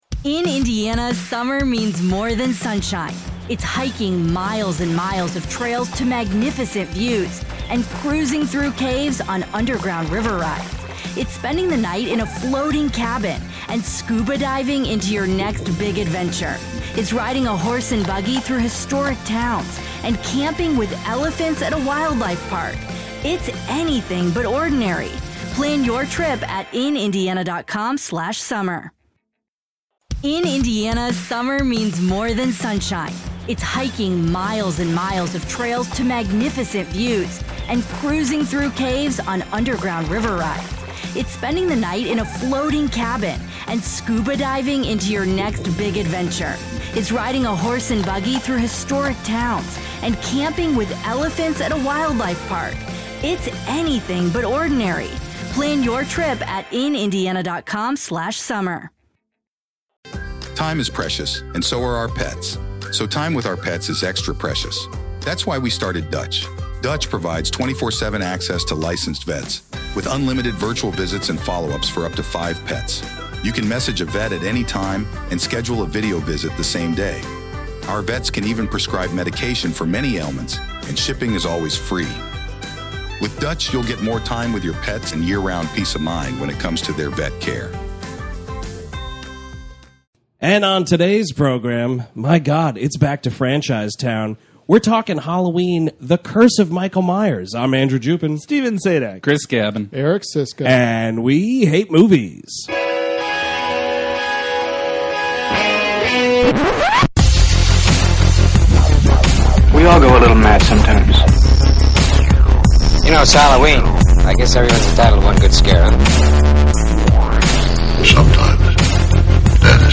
PLUS: Several wishy-washy Donald Pleasence impressions!